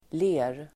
Uttal: [le:r]